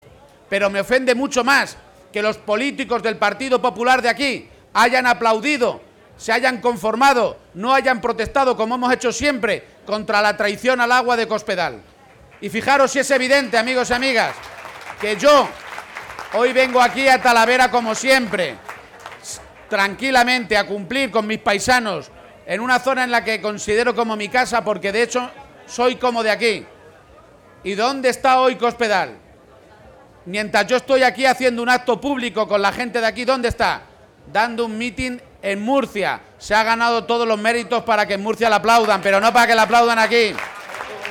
En un acto en la caseta del PSOE en el recinto ferial de Talavera de la Reina (Toledo), García-Page ha reiterado que la candidata del PP a la Junta, María Dolores de Cospedal, «no ha presentado su programa» y no se sabe si es «bueno ni malo, ni blanco ni negro ni de arriba o de abajo», algo que ha insistido en que es «indecente».